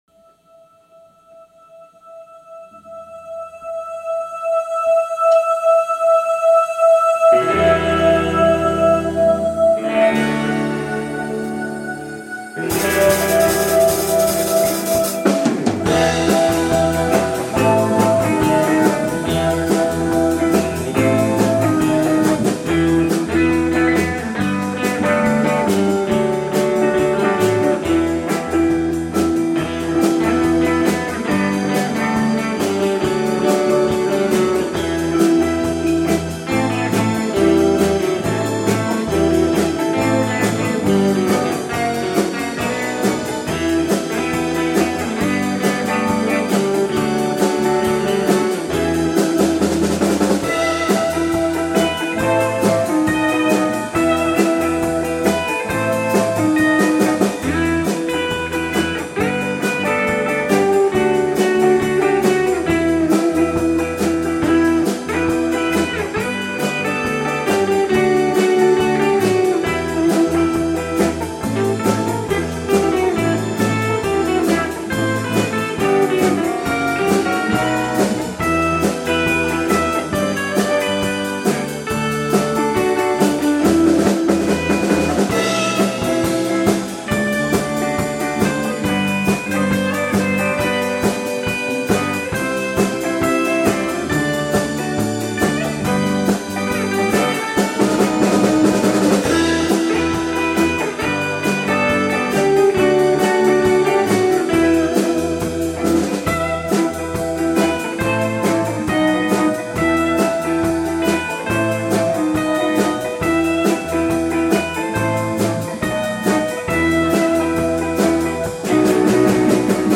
１２月９日（日）高槻市の「Nashville West」にて「ザ・アップル・スター・ライブ」に出演しました